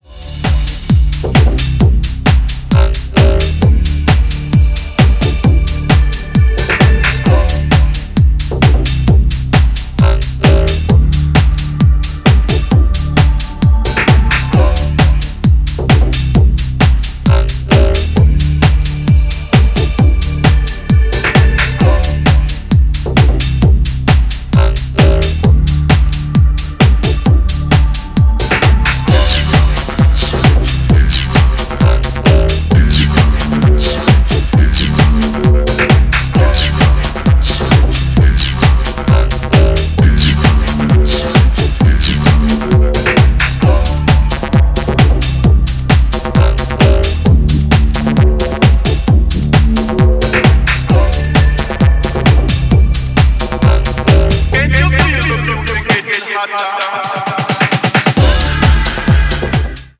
圧迫感の強いボトム上でダーク&プログレッシヴなスペクトラムを展開していく必殺のエレクトロ・ハウス